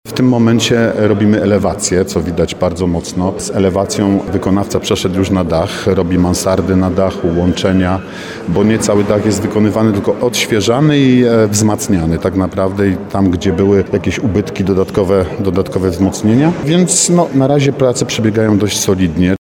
– Na razie wszystko idzie jednak zgodnie z planem – mówi wiceprezydent Nowego Sącza, Artur Bochenek.